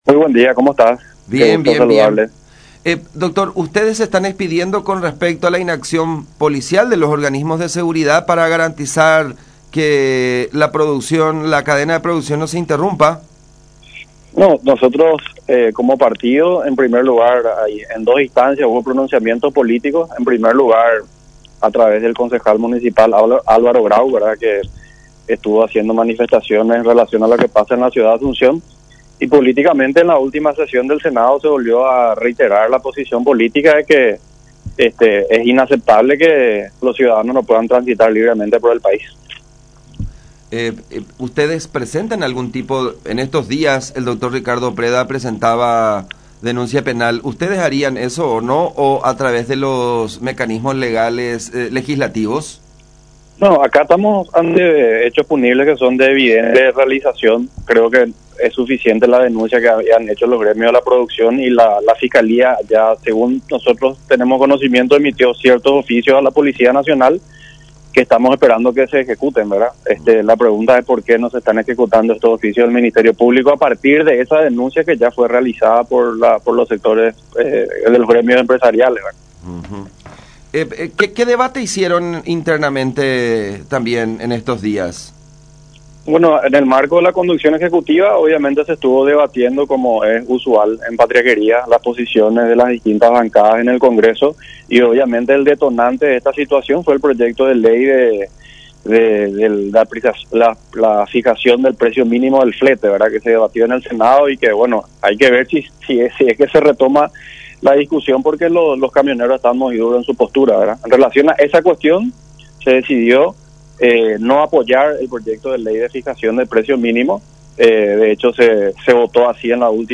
“La pregunta es porque nos están ejecutando estos oficios del Ministerio Público a partir de esa denuncia que ya fue realizada por los gremios empresariales”, agregó en contacto con Todas las Voces de Radio La Unión R800 AM.